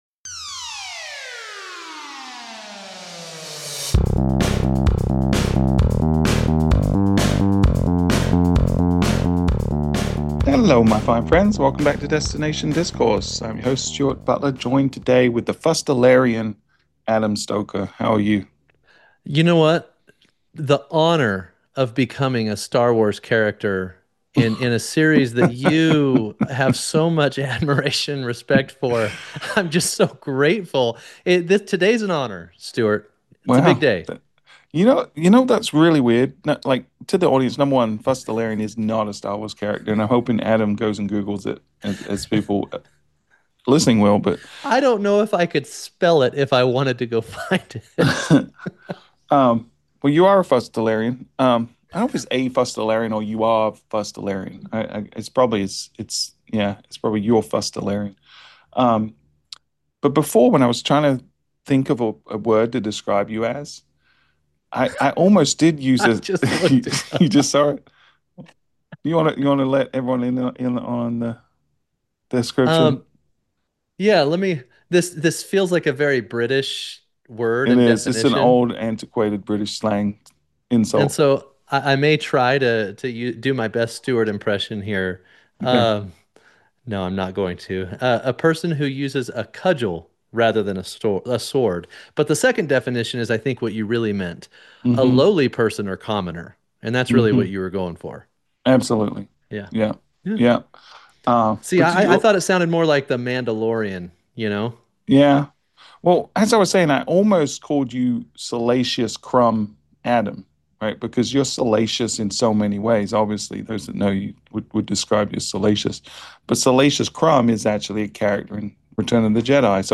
This is an unfiltered conversation about the pendulum swing from clicks back to brand, the blending of paid, earned, and owned media, and why attention, not just impressions, will be the new currency.